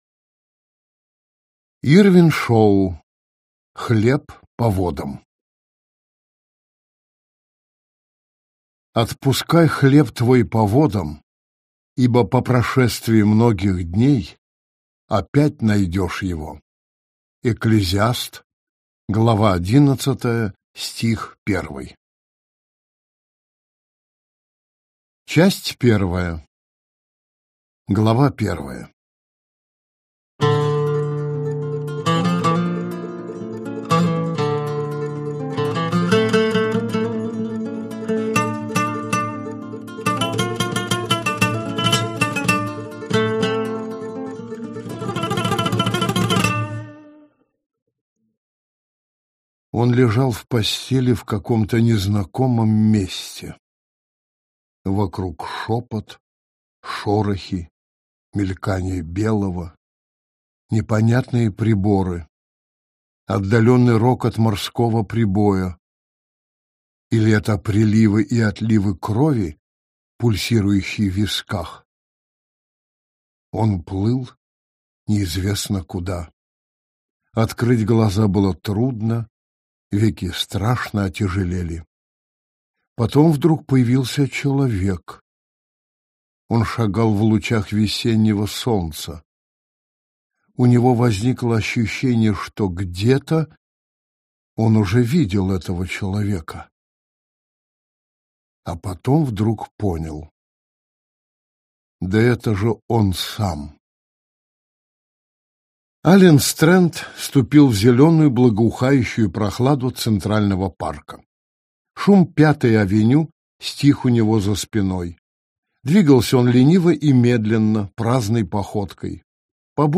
Аудиокнига Хлеб по водам | Библиотека аудиокниг